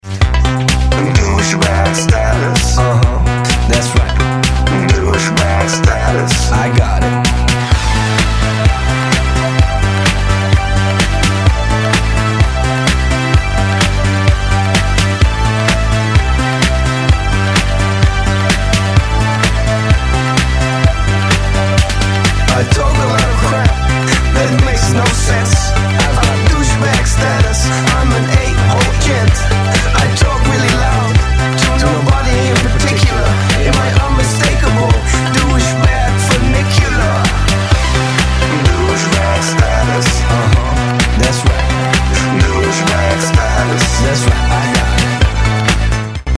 Lo-fi techno funk and fucked up house from Amsterdam.
dirty electronic funk